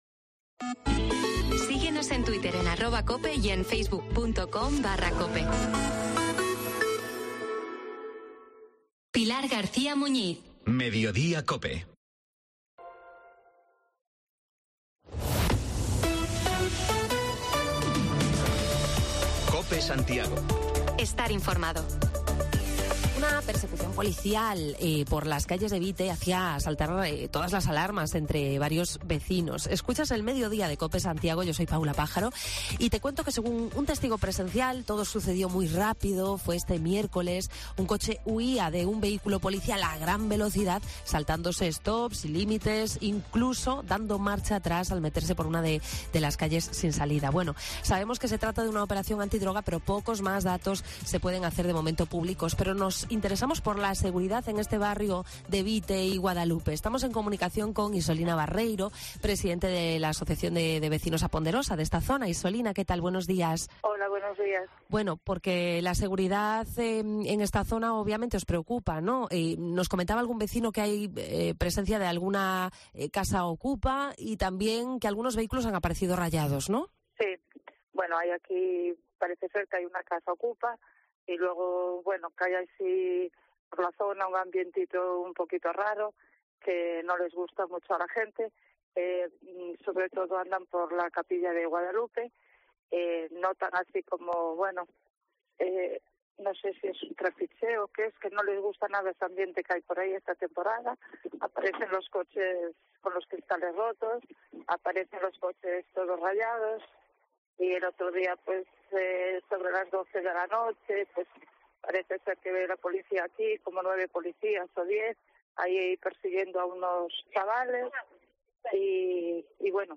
Nos vamos al barrio de Vite-Guadalupe por inseguridad en la zona: trapicheo, escasa luz y una larga lista de asignaturas pendientes del gobierno local que nos trasladan los vecinos. Manuel Taboada, alcalde de O Pino, nos cuenta lo que supondrá contar con un servicio comarcalizado de bomberos